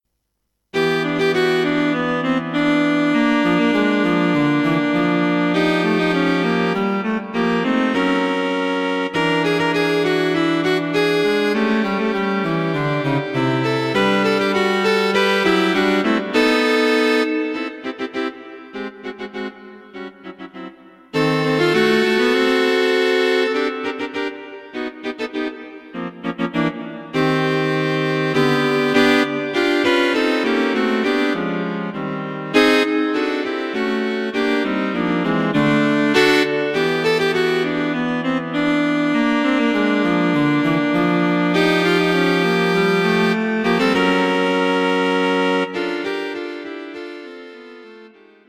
A cheerful piece for three cellos.